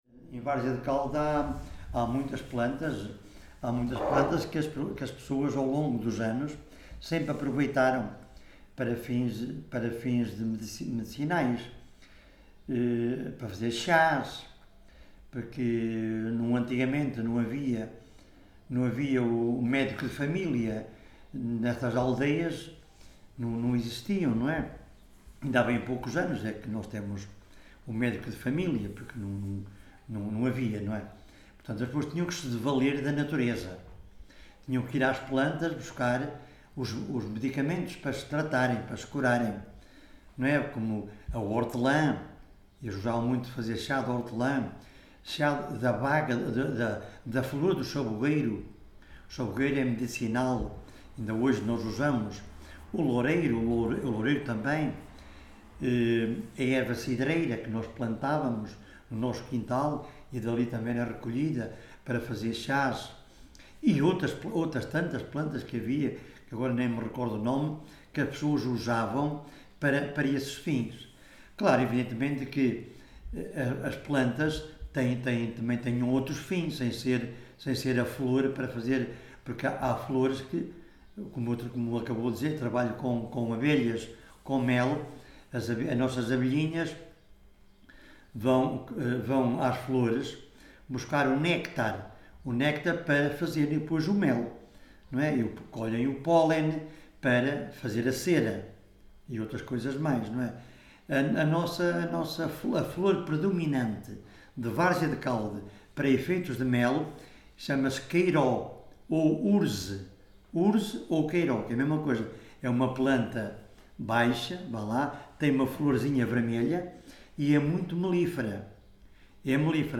Várzea de Calde, primavera de 2019. Registo sonoro integrado numa série de gravações realizadas para o projeto Viseu Rural 2.0 e para o Arquivo da Memória de Várzea de Calde, cujo tema principal são diversos usos e conhecimentos sobre plantas: medicina, culinária, construção de ferramentas...
Tipo de Prática: Inquérito Oral